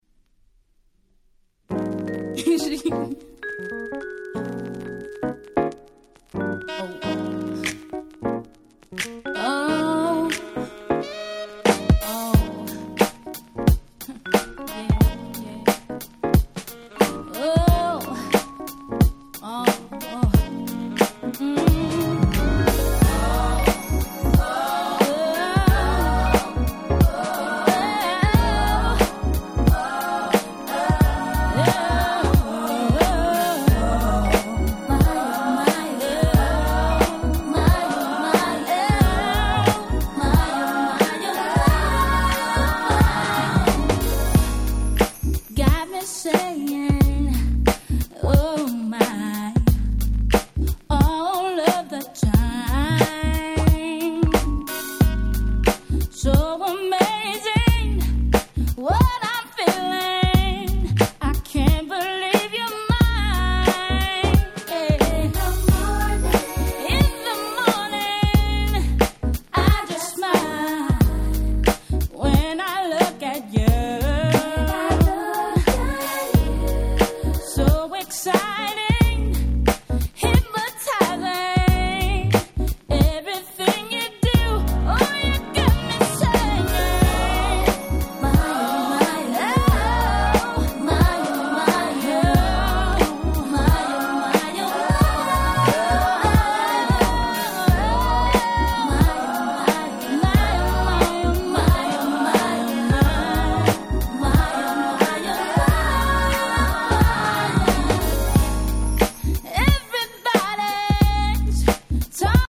99' Nice R&B !!
JazzyでCrystalな気持ちの良いMid R&B !!